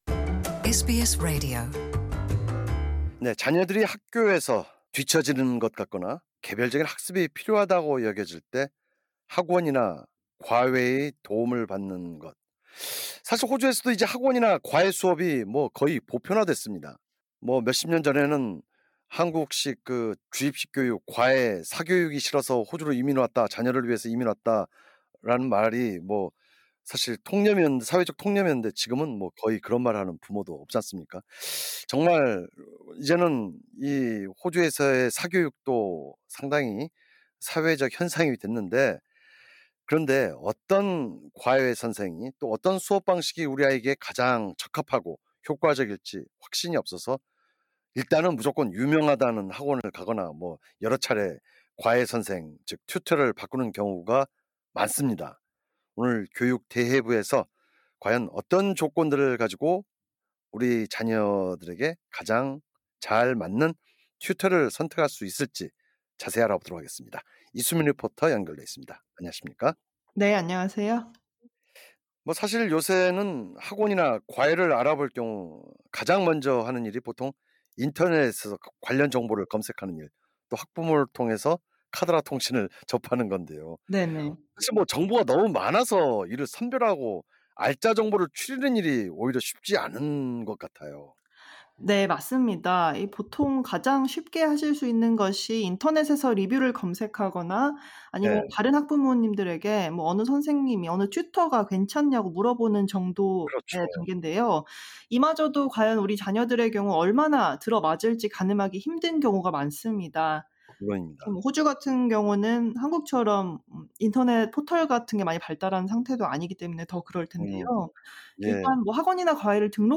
진행자: 그렇죠.